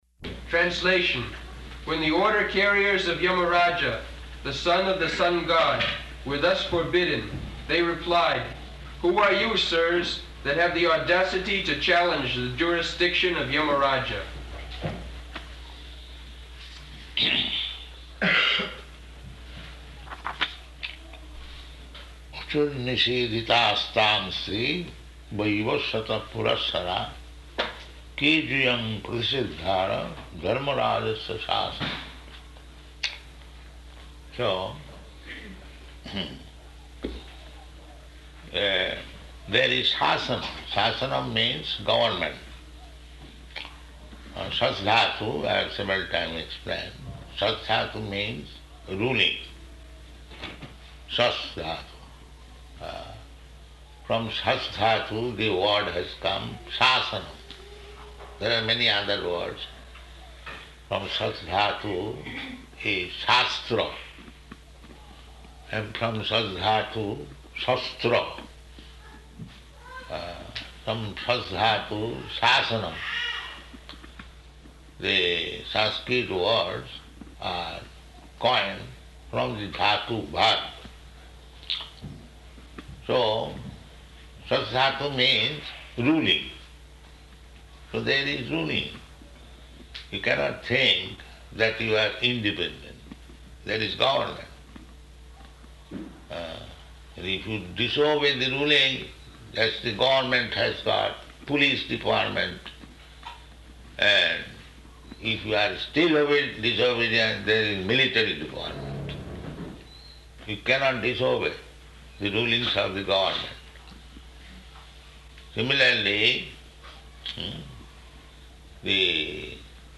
Location: Honolulu